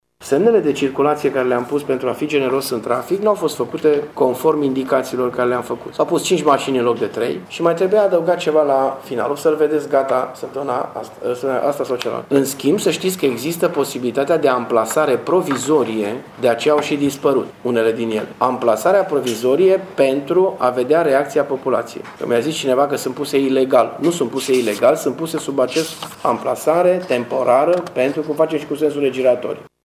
Semnele au fost montate, însă primarul spune acum că ele nu sunt conform intenţiei sale şi că vor fi modificate. Acesta a subliniat că montarea lor provizorie a fost cât se poate de legală: